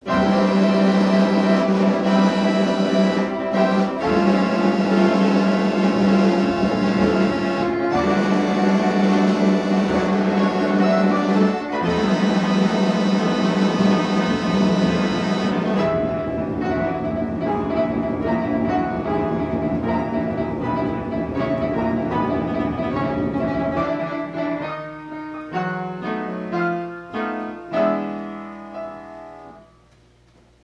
POPPER WELT PIANO STYLE "O" ORCHESTRION